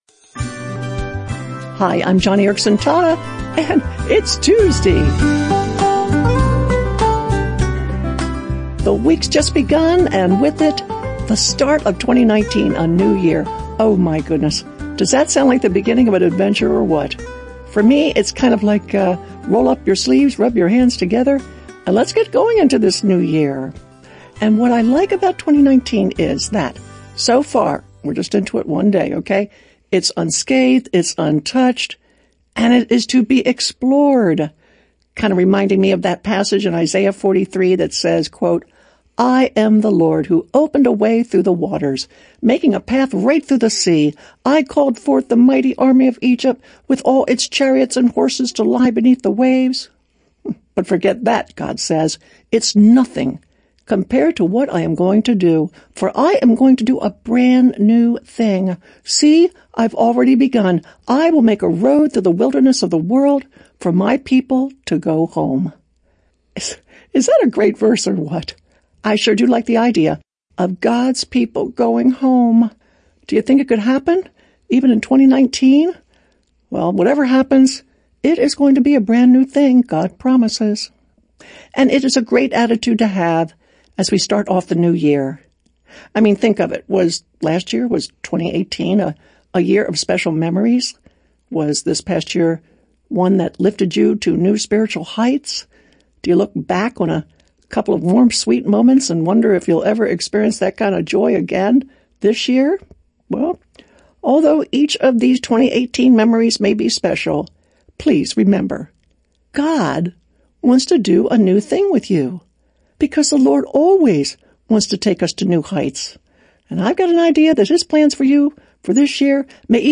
By |Published On: January 1, 2019|Categories: 4-Minute Radio Program|